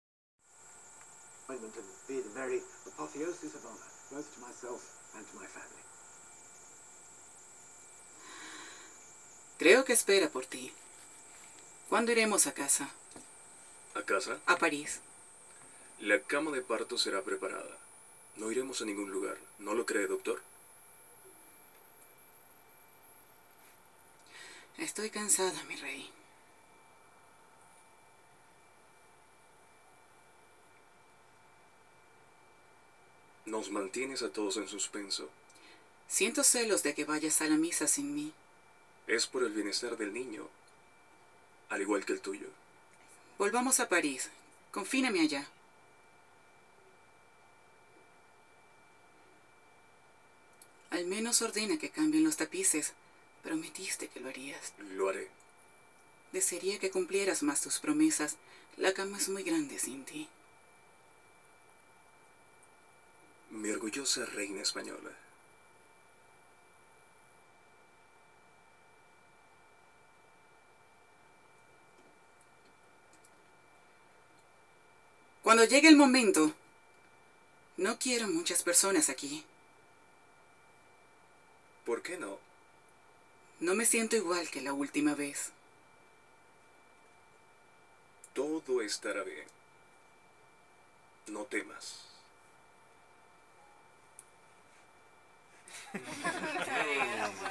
Velvety voice, soft, sweet and femenine but strong, jovial and emotional.
Sprechprobe: eLearning (Muttersprache):
Sprechprobe: Industrie (Muttersprache):
My voice is a particular tone that is difficult to achieve, it is sharp and elegant.